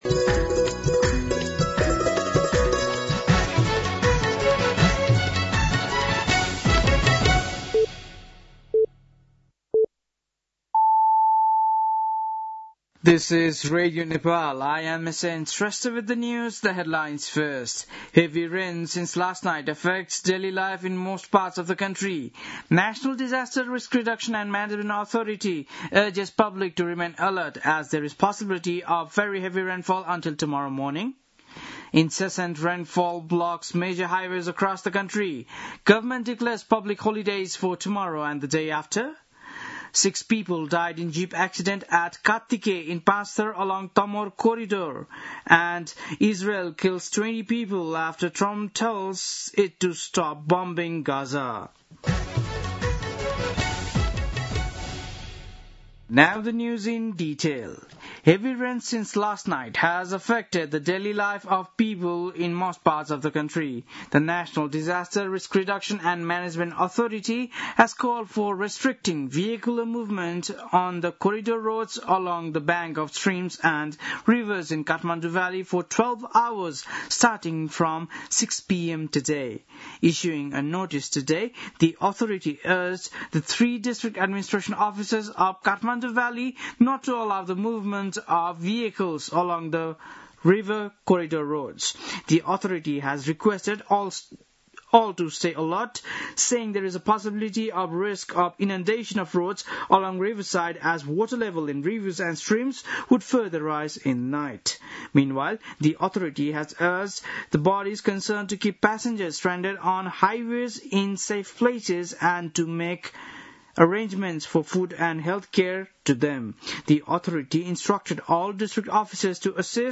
बेलुकी ८ बजेको अङ्ग्रेजी समाचार : १८ असोज , २०८२